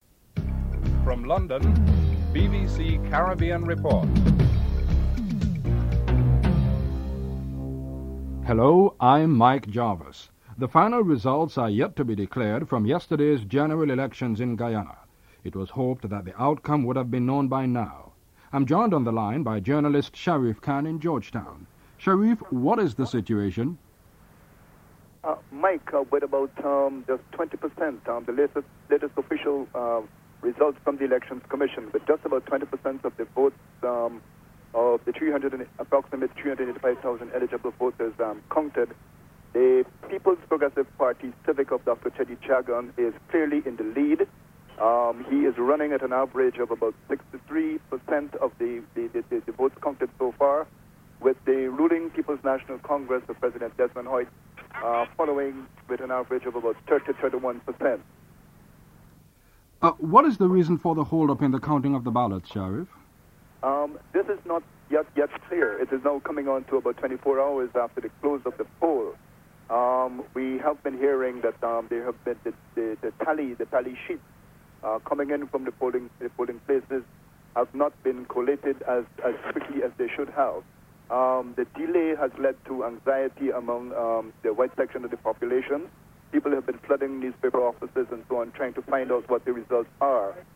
3. David Peterson, Chairman of the Commonwealth Observer Team and Ed Broadbent, member of the Carter Center Observer Team both agree that despite administrative problems and other issues, Guyana’s elections was a success. (07:24-11:07)